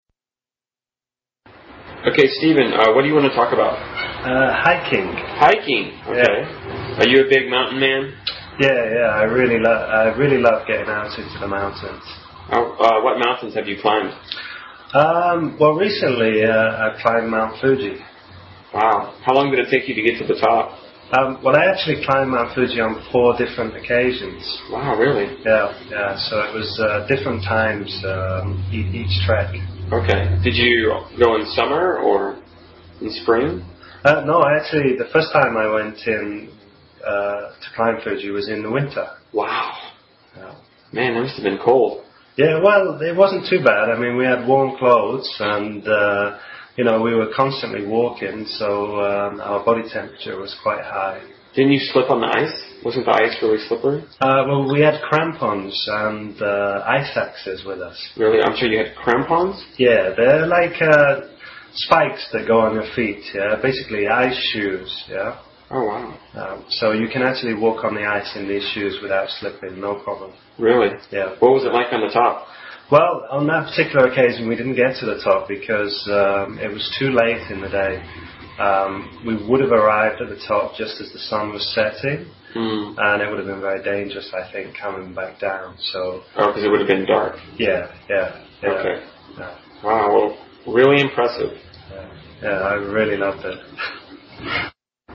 实战口语情景对话 第121期:攀登富士山 Climbing Fuji 听力文件下载—在线英语听力室